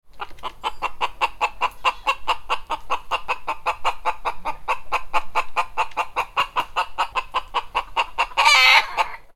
Animal Sounds / Chicken Sounds / Sound Effects
Hen-clucking-sound-effect.mp3